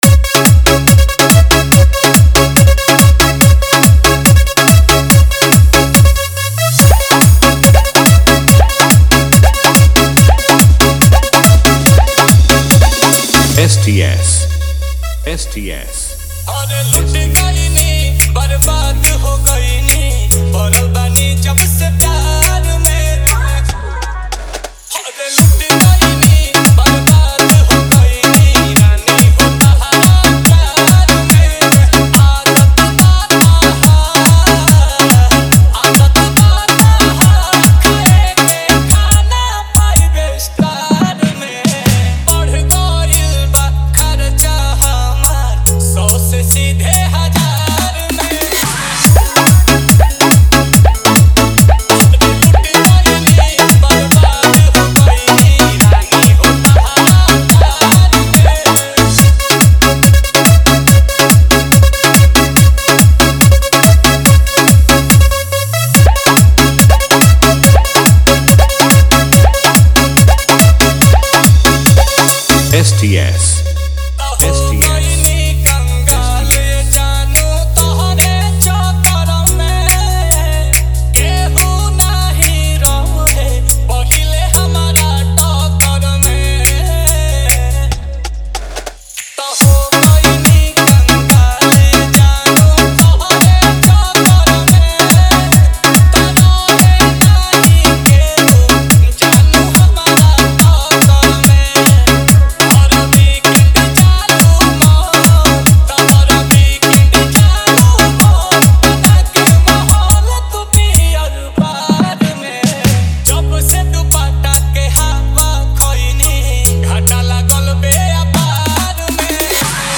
Bhojpuri DJ remix mp3 song